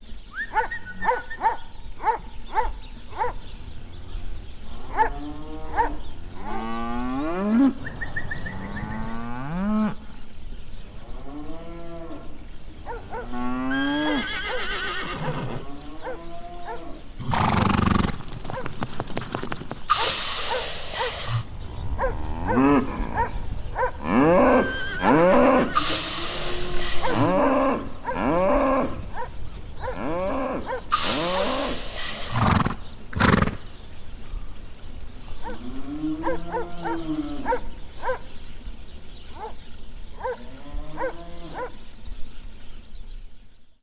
Amanecer en el campo